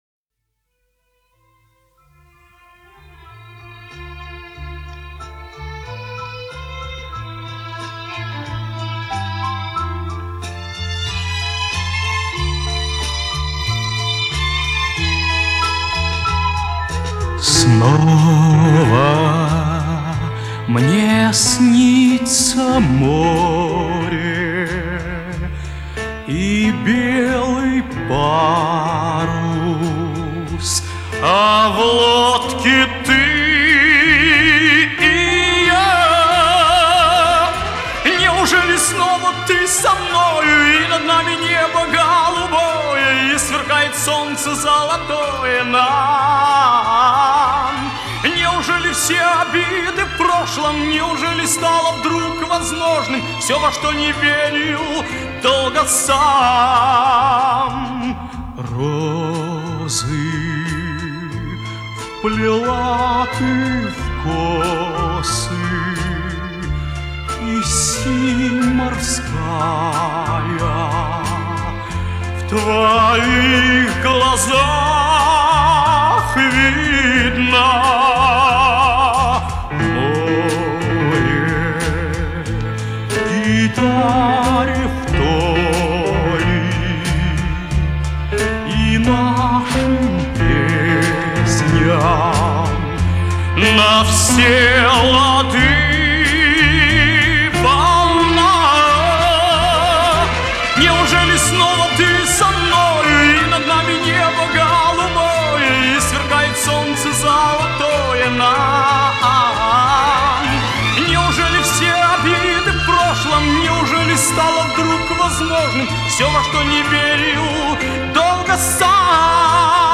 это кавер